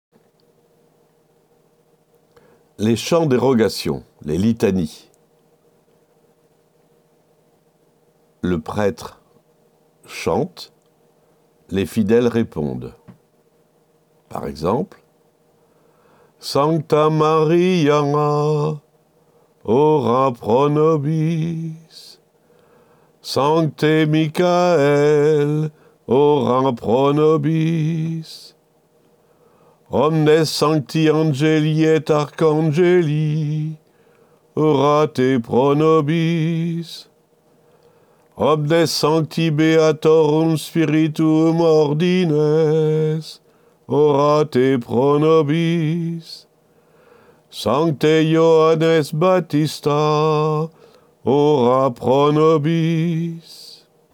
On y chantait les litanies, en tête, le curé et les choristes, suivis des fidèles.